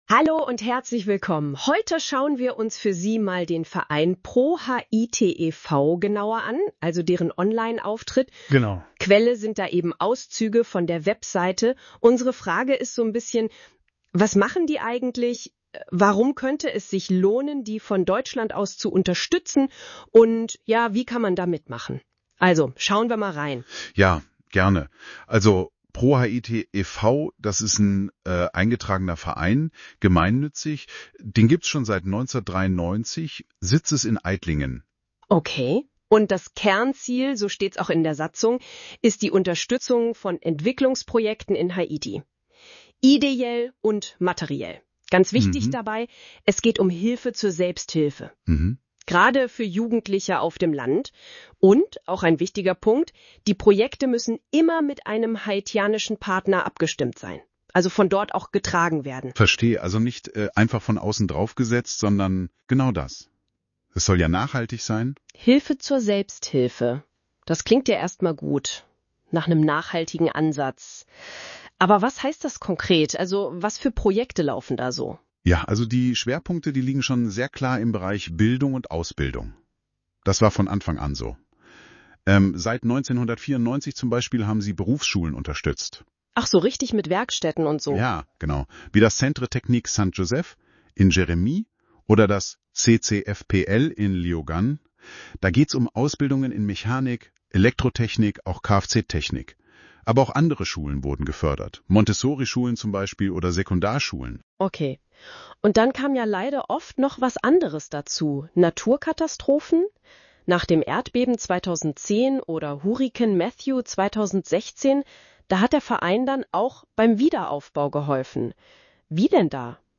Dieser Podcast wurde durch eine KI generiert und fasst wesentliche Aspekte dieser Homepage zusammen.